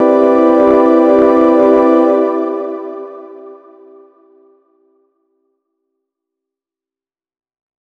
003_LOFI CHORDS MAJ9_2.wav